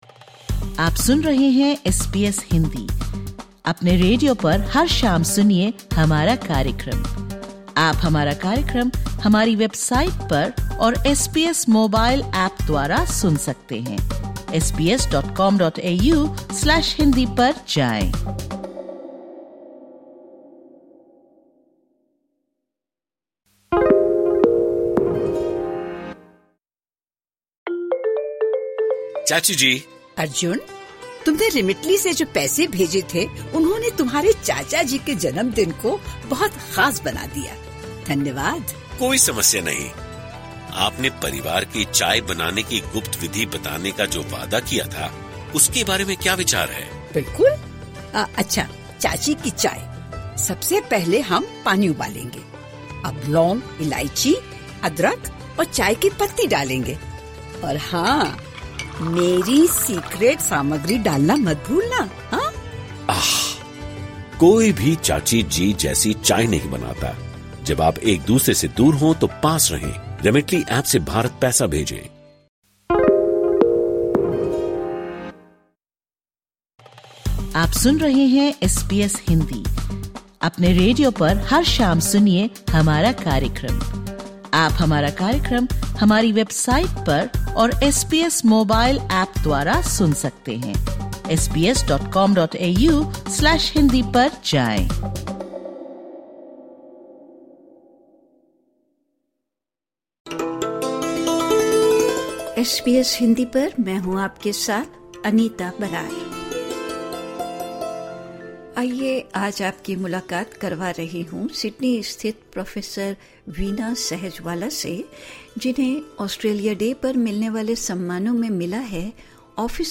सिडनी स्थित साइंटिया प्रोफेसर वीना सहजवाला को इस वर्ष ऑस्ट्रेलिया दिवस पर विज्ञान, संधारणीय सामग्री अनुसंधान और प्रौद्योगिकी तथा अपशिष्ट प्रबंधन के लिए विशिष्ट सेवा के लिए 'ऑफिसर ऑफ द ऑर्डर ऑफ ऑस्ट्रेलिया' (AO) सम्मानित किया गया है। इस पॉडकास्ट में एस बी एस हिन्दी से बातचीत करते हुये सहजवाला ने अपनी आविष्कारक पॉलीमर इंजेक्शन तकनीक, जिसे 'ग्रीन स्टील' के रूप में जाना जाता है, उसकी जानकारी साझा करते हुये अपने दूसरे रिसर्च प्रोजेक्ट की भी जानकारी दी।